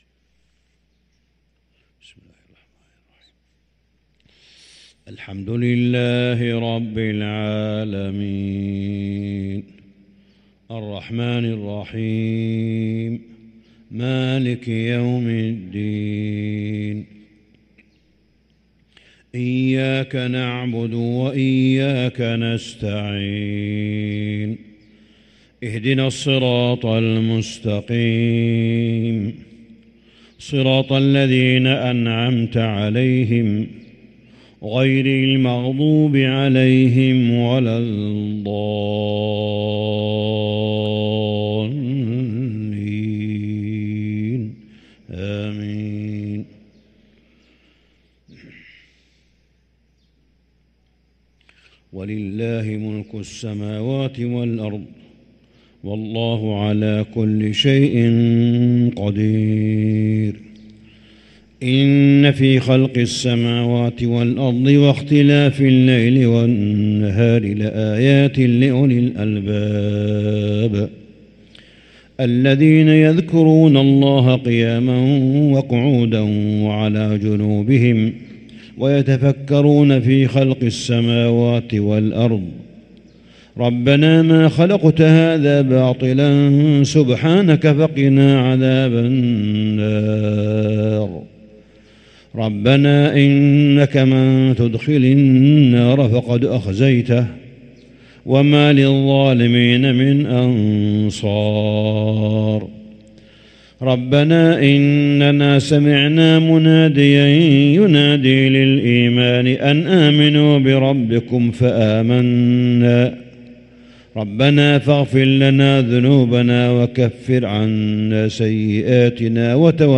صلاة الفجر للقارئ صالح بن حميد 17 رمضان 1444 هـ